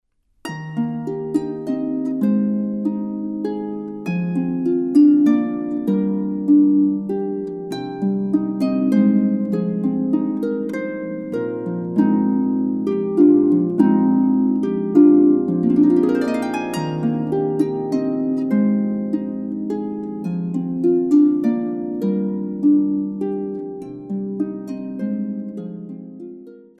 LOWER INTERMEDIATE, ALL HARPS.